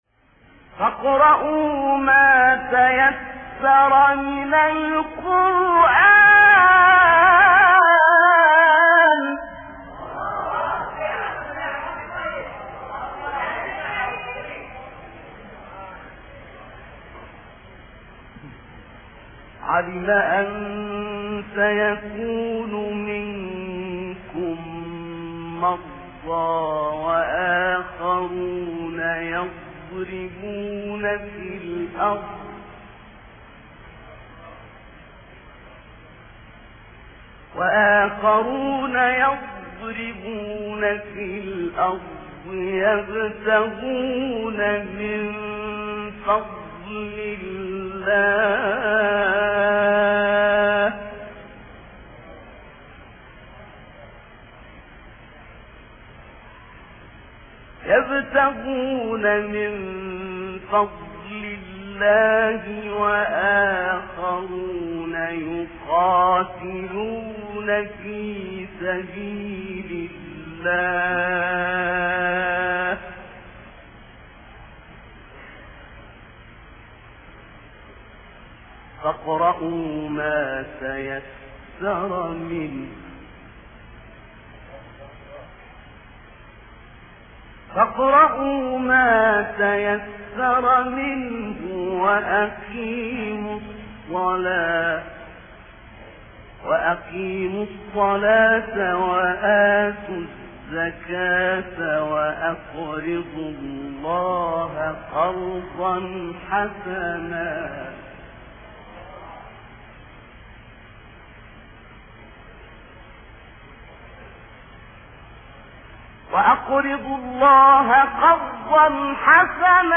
آیه 20 سوره مزمل استاد نقشبندی | نغمات قرآن | دانلود تلاوت قرآن